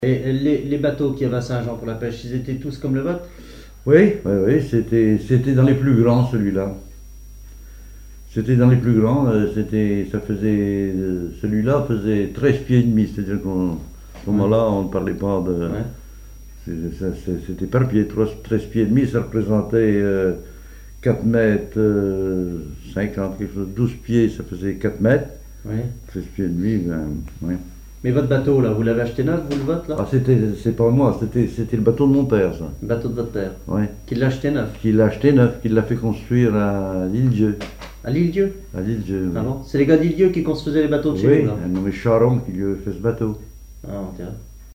Enquête Arexcpo en Vendée
Témoignage